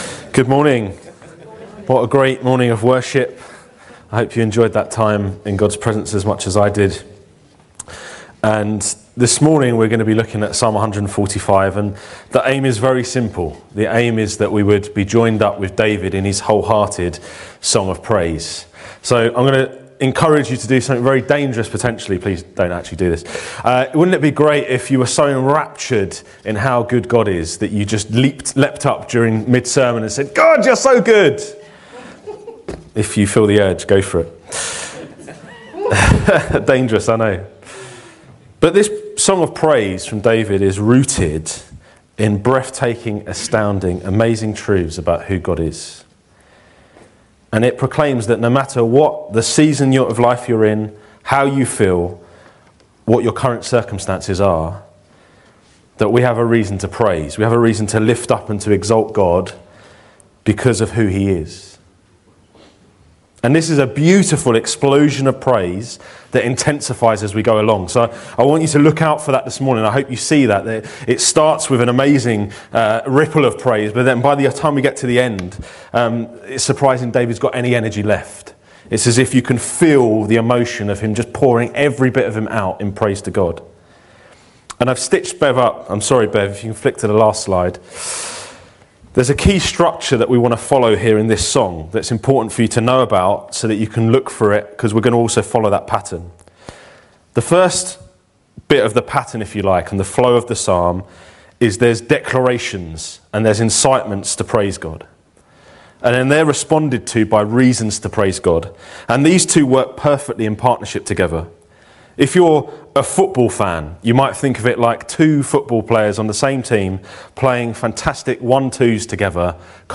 This sermon encourages us to lift up our voices to God in free and exuberant praise.